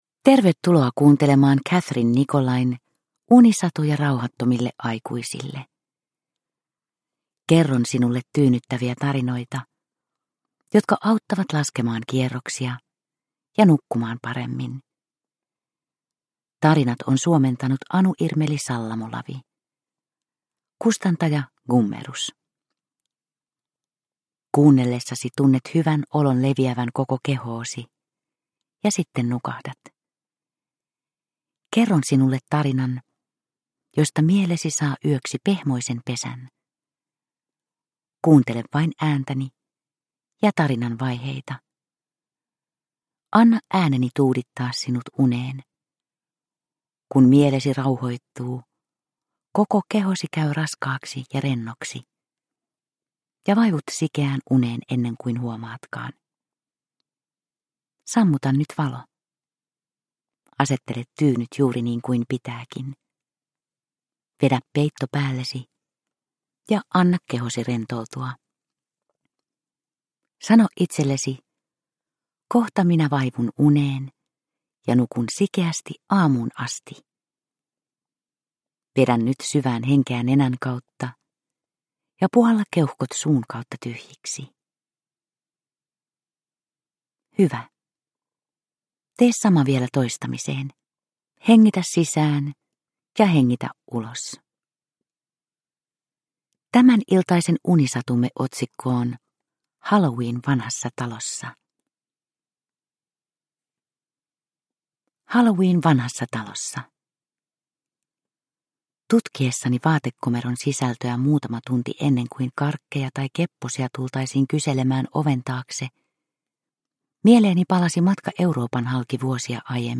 Unisatuja rauhattomille aikuisille 3 - Halloween vanhassa talossa – Ljudbok – Laddas ner